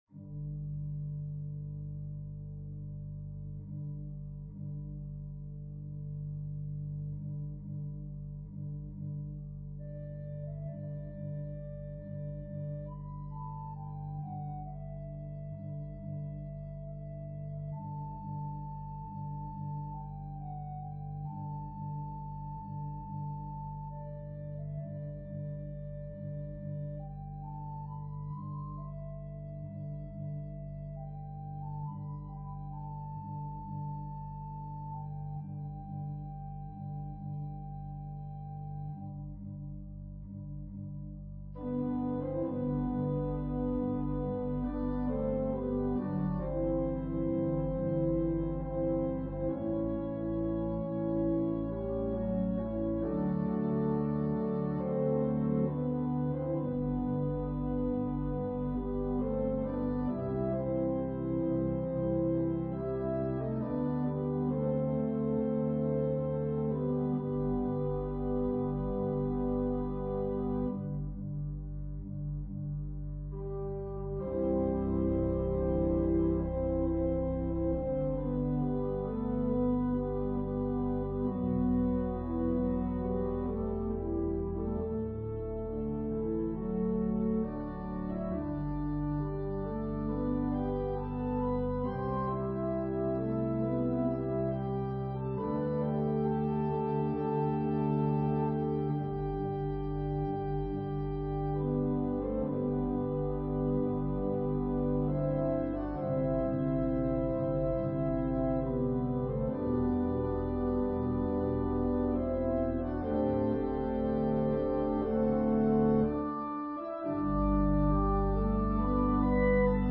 Organ/Organ Accompaniment
Voicing/Instrumentation: Organ/Organ Accompaniment We also have other 37 arrangements of " Brightly Beams Our Father's Mercy ".